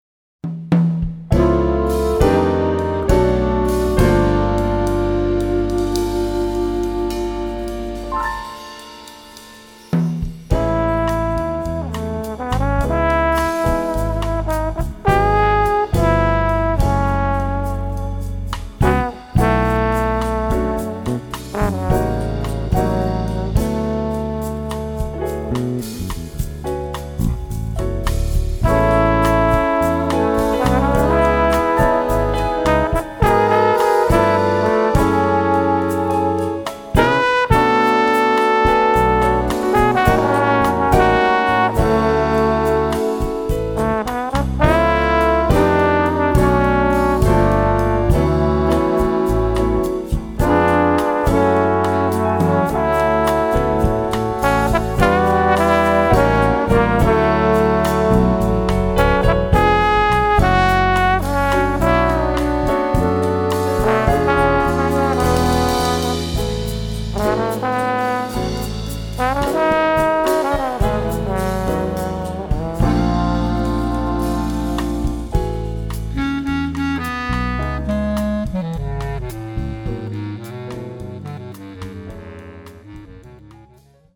Category: combo (nonet)
Style: bossa nova
Solos: open